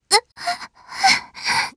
Frey-Vox_Dead_jp.wav